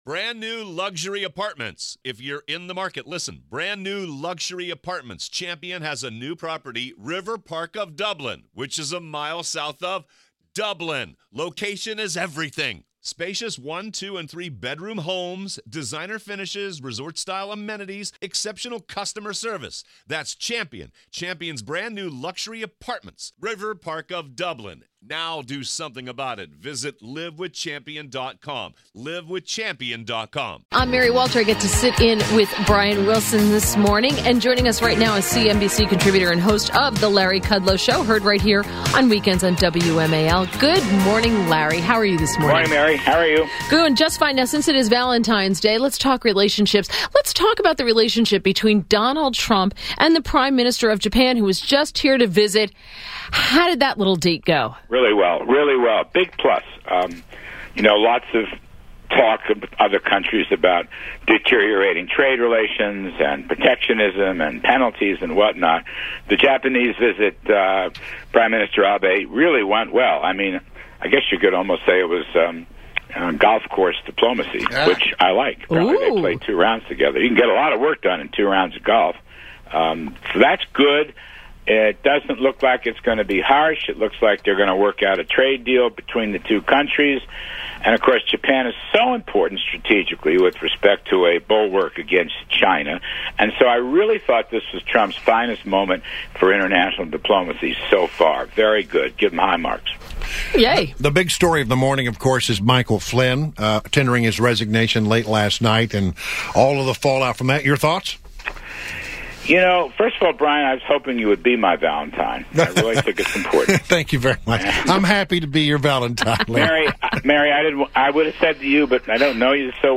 WMAL Interview 02.14.2017; Larry Kudlow
Larry Kudlow; CNBC Senior Contributor and host of The Larry Kudlow Show on WMAL Saturdays at 7 pm.